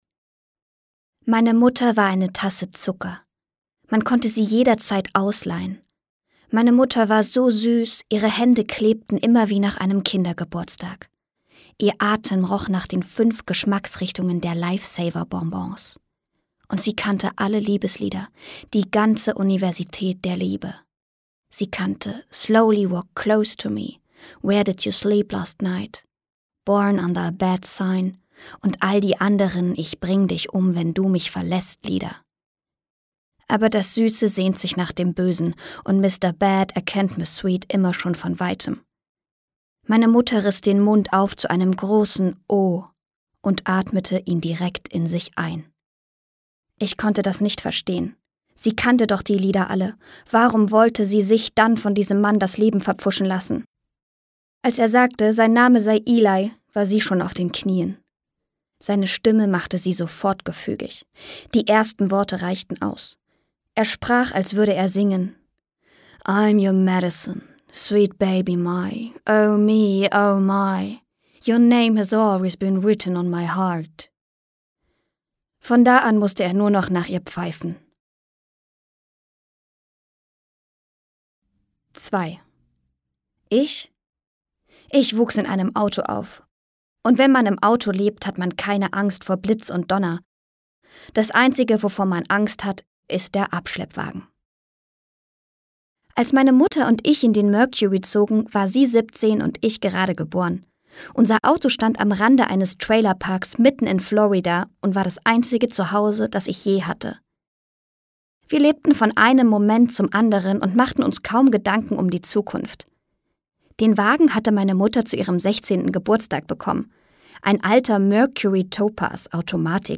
Jennifer Clement schreibt vom Band zwischen Mutter und Tochter und dem Waffenwahnsinn ihrer Heimat, sie erzählt in strahlenden Bildern eine Geschichte, in der Liebe und Gewalt, Magie und Fakt, Sorge und Freude haltlos ineinander fallen.Das literarische Stimmungsbild einer ganzen Nation."Ich finde die Stimme ganz wunderbar, unglaublich mädchen- ja geradezu zauberhaft.